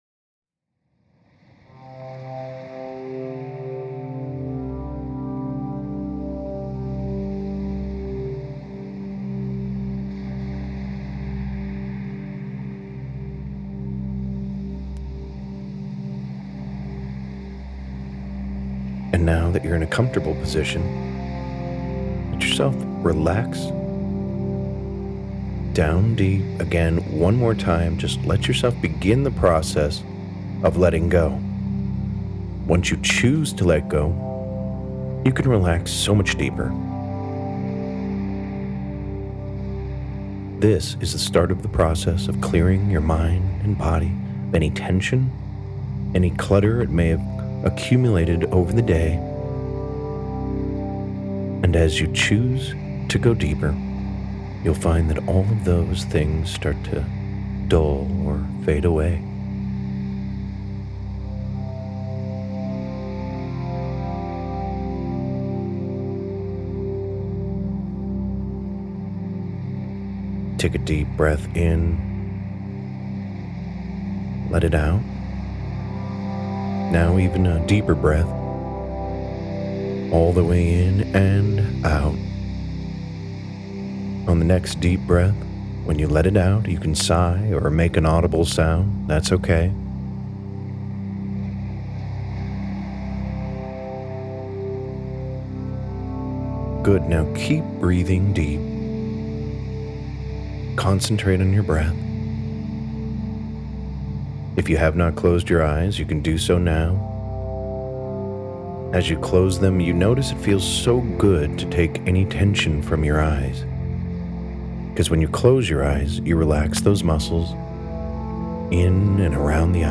The Art of Manifestation_Sleep Induction
The+Art+of+Manifestation+Sleep+Induction.mp3